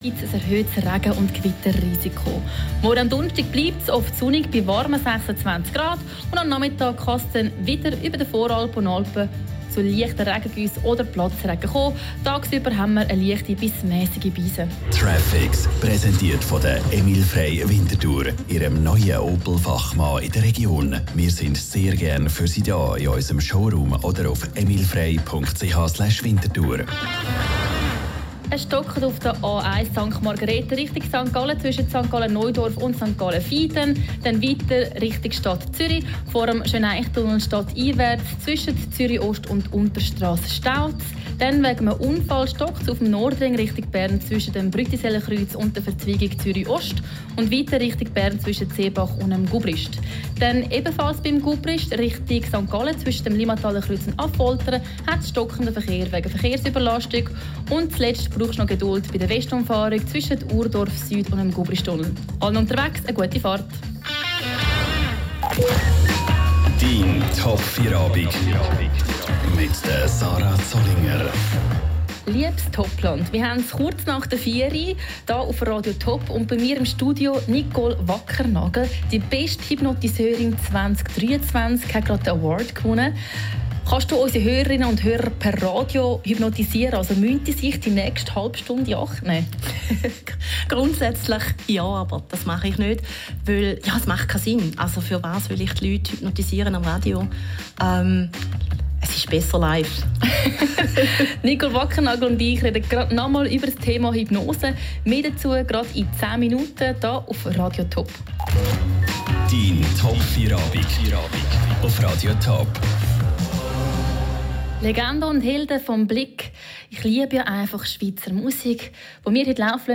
Radio-Interview bei Radio TOP zum Welttag des Nichtrauchens – Hypnose & Rauchstopp
Radiointerview bei Radio TOP zum Thema Rauchstopp und Nichtraucher werden mit Hypnose